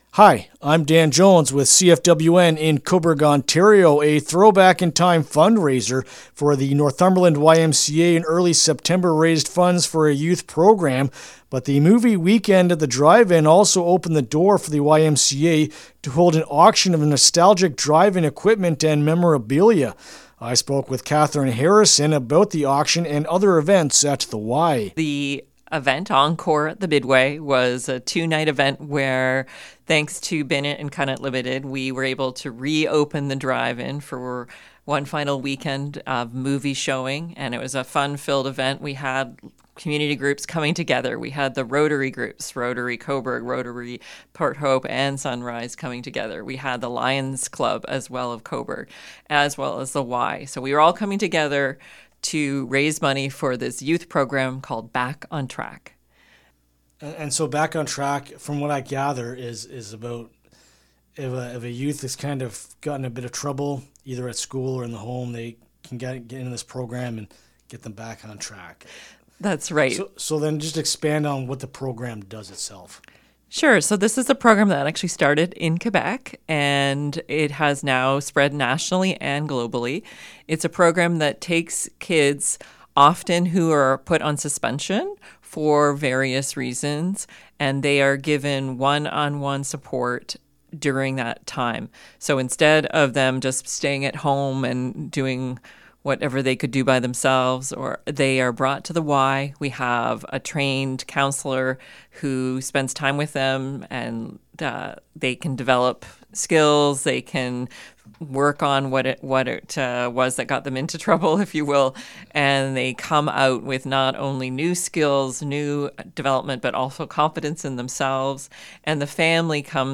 Northumberland-YMCA-Fundraiser-Interview-LJI.mp3